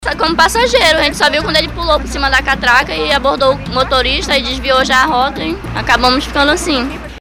Os dados da Secretaria de Segurança Pública do Amazonas mostram que Manaus teve uma redução de 29% no número de assaltos à ônibus do transporte coletivo na capital amazonense. No áudio abaixo, uma passageira relata o sufoco que passou durante uma ação criminosa dentro do ônibus que ela viajava com a família.